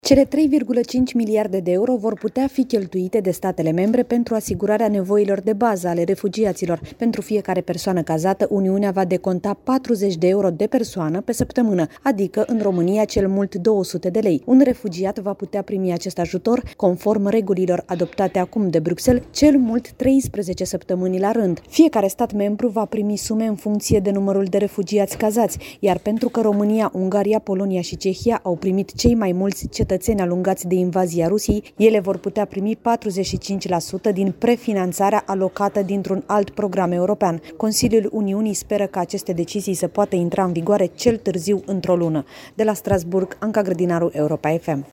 De la Strasbourg